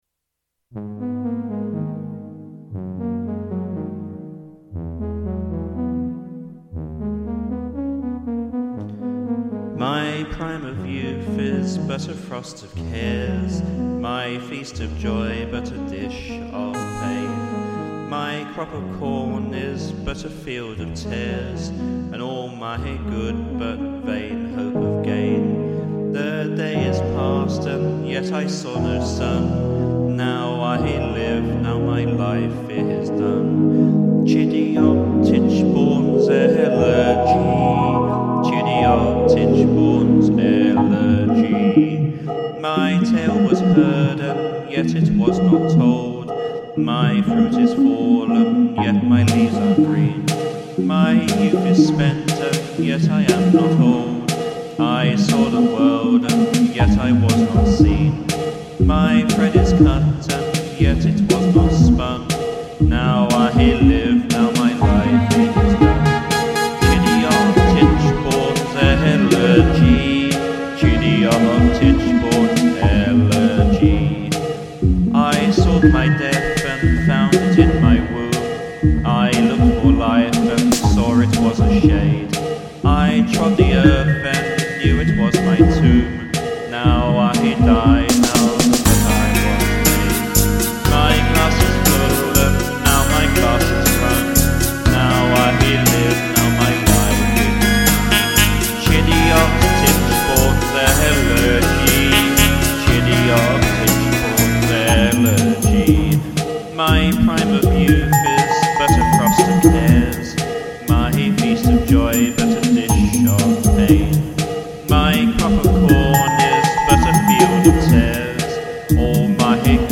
electro music duo
vocal
This is an early take not used on our CD